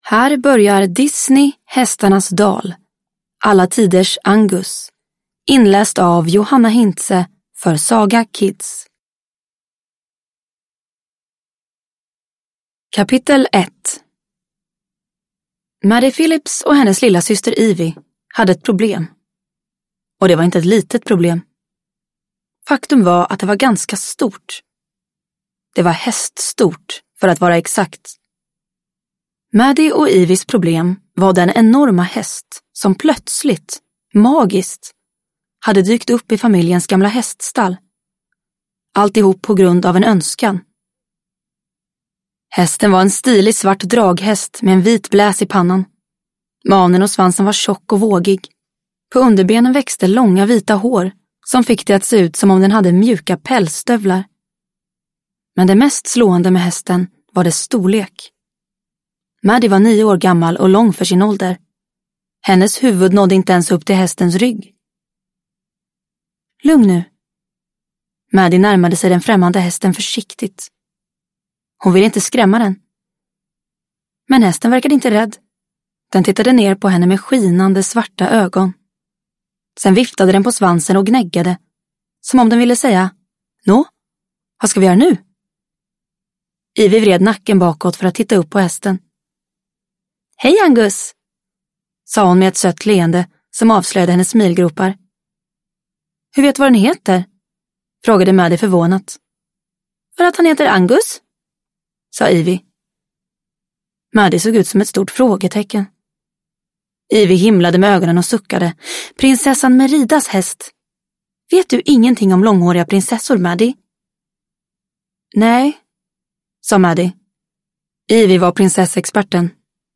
Hästarnas dal – Alla tiders Angus – Ljudbok